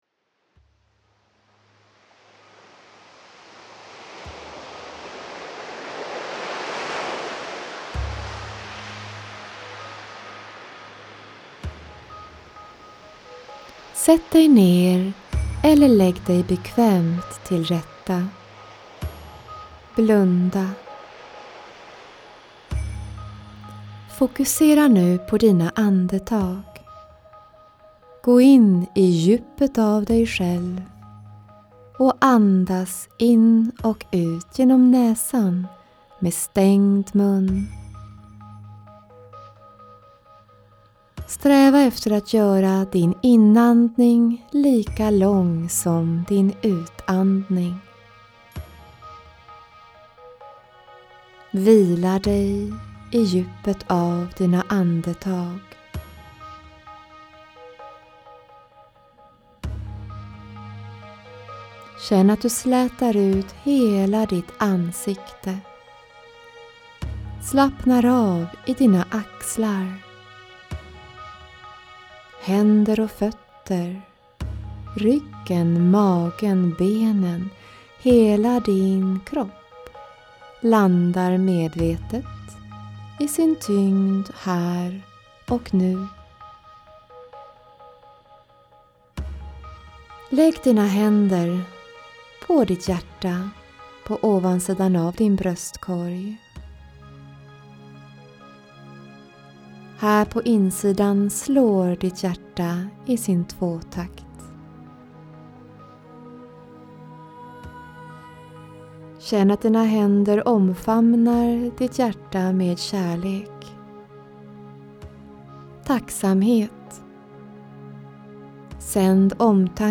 En guidad meditation när du har en tuff dag